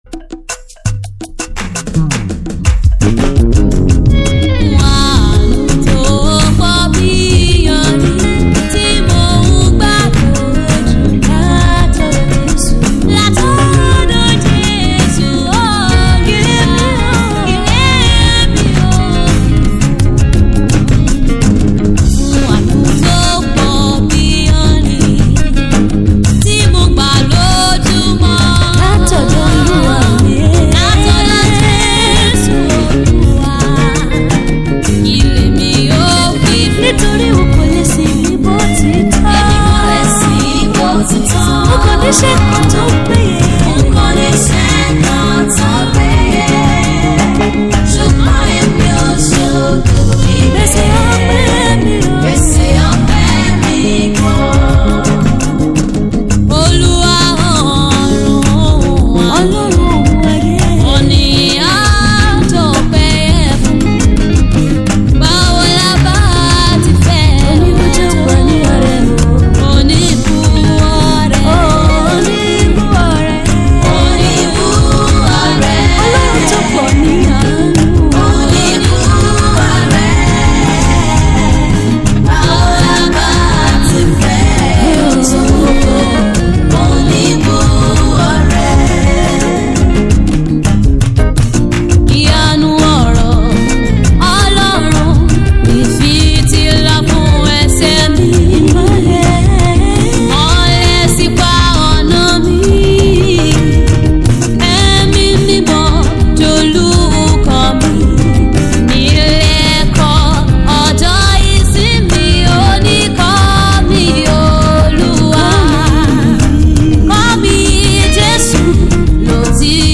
contemporary worship music